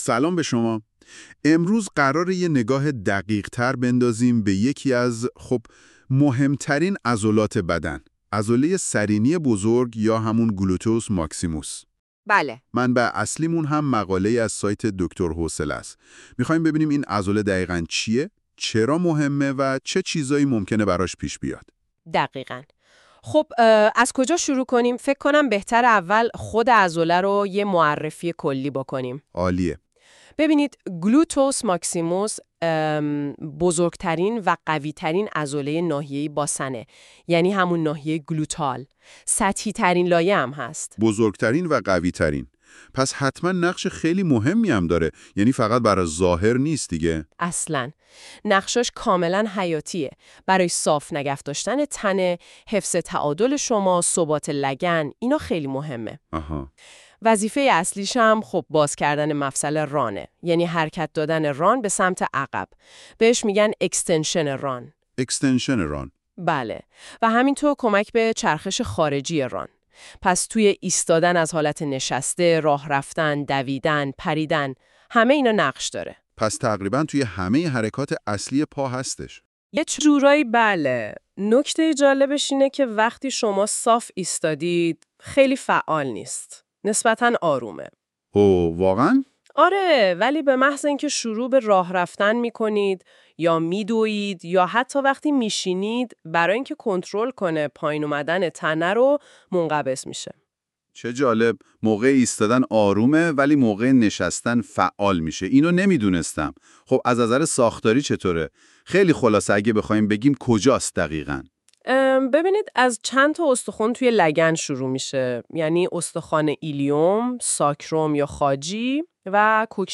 در این پادکست، طی یک گفت‌وگوی دوطرفه و شنیدنی، به معرفی کامل عضله گلوتئوس ماکسیموس پرداخته‌ایم و به تمامی پرسش‌هایی که ممکن است در ذهن شما شکل گرفته باشد، پاسخ داده‌ایم.